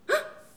hein-surprise_01.wav